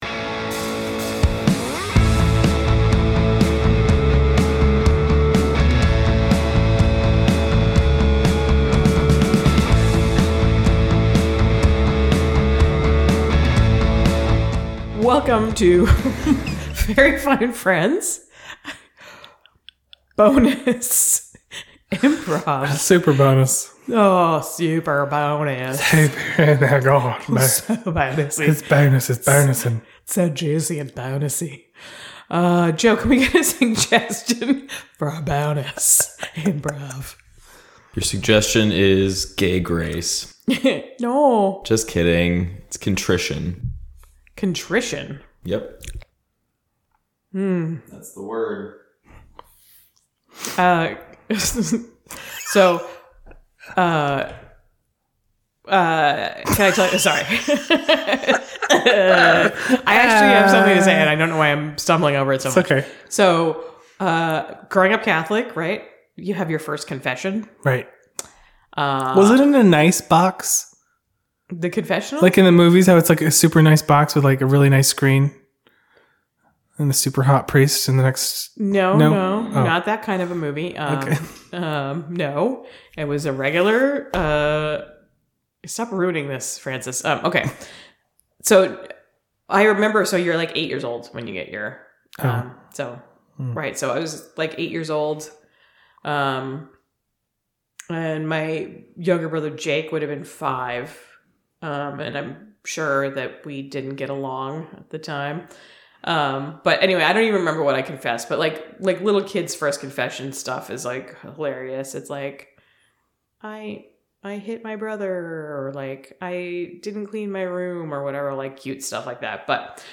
improv